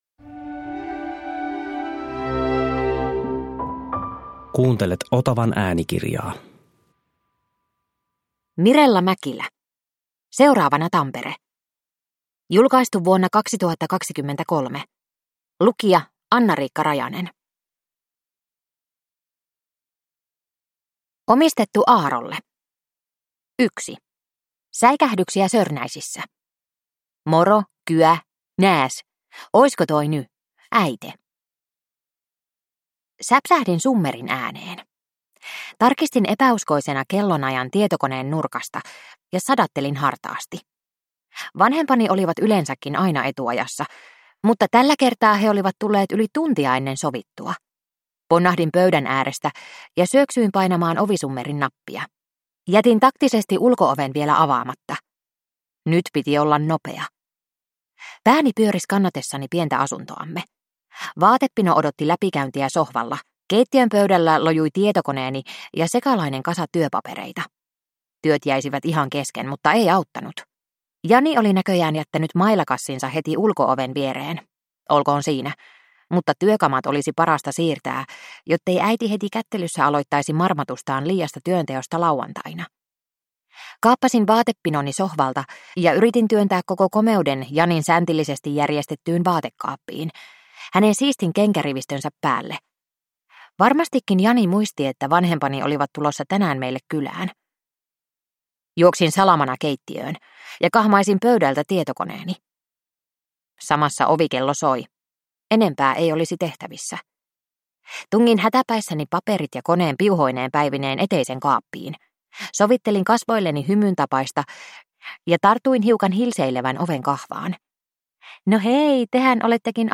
Seuraavana Tampere – Ljudbok – Laddas ner